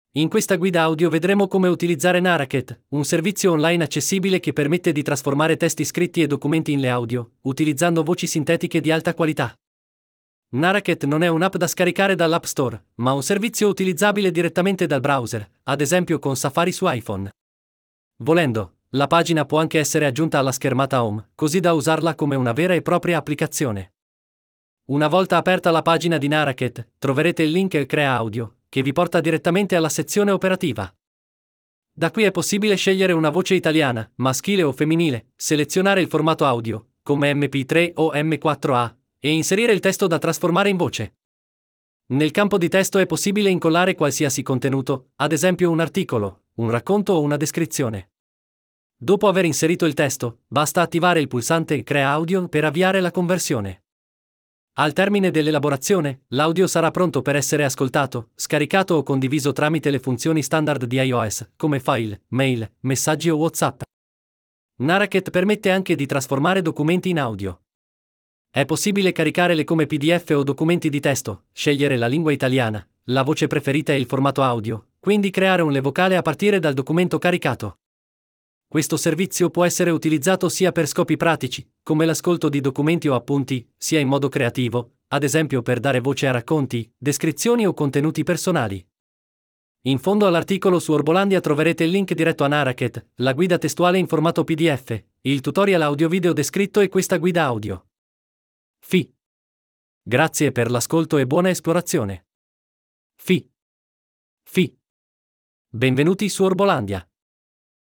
una guida audio, per chi preferisce ascoltare.